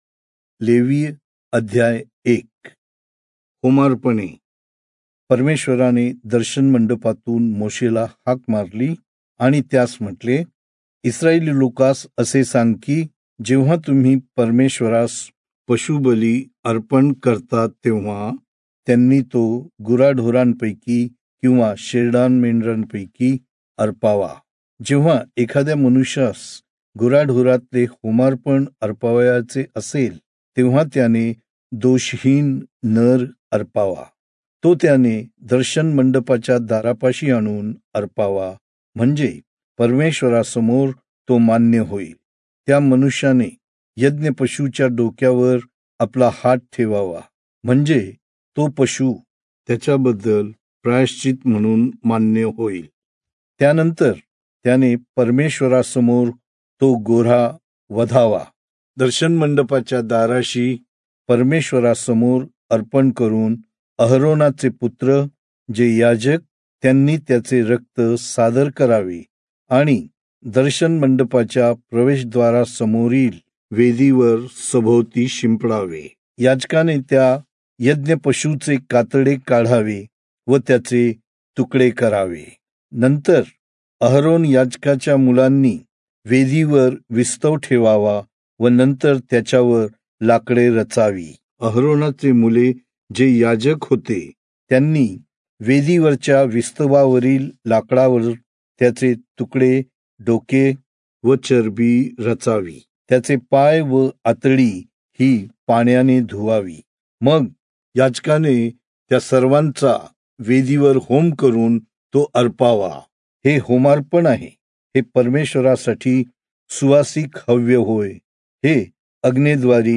Marathi Audio Bible - Leviticus 9 in Irvmr bible version